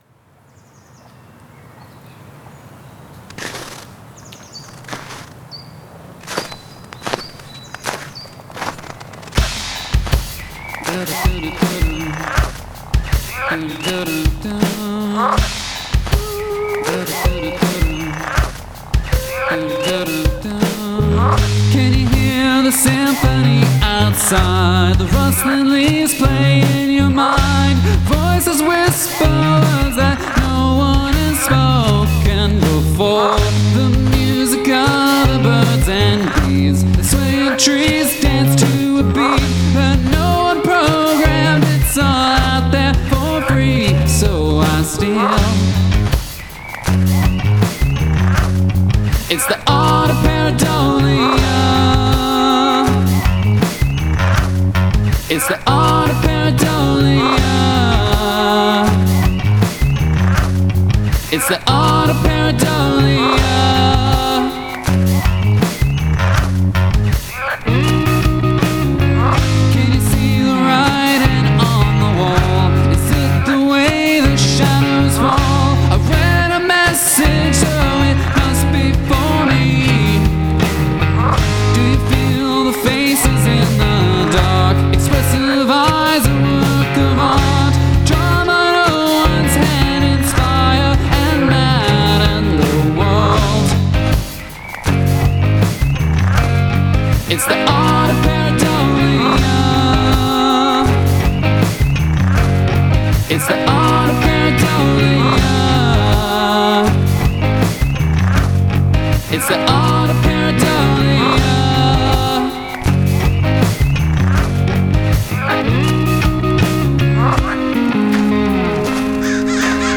Use of field recording